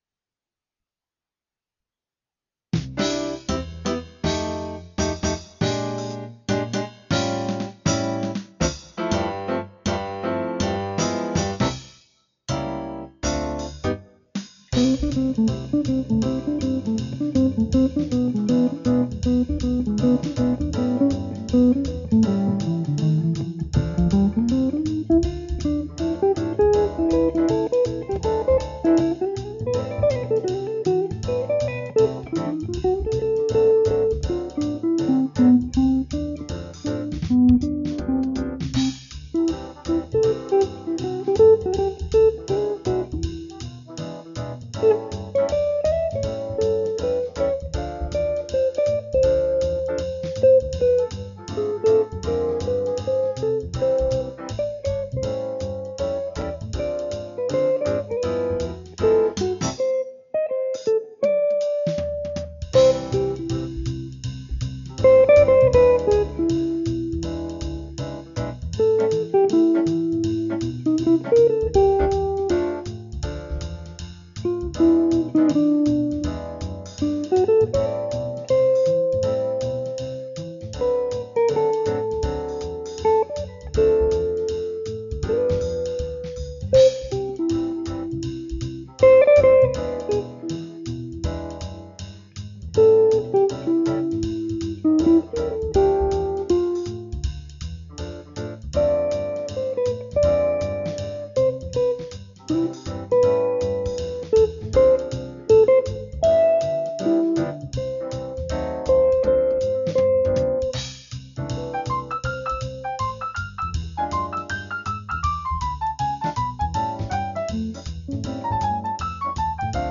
Sacha Distel 7 cordes 1989
• 2 micros Benedetti
Vous pouvez entendre la guitare ici :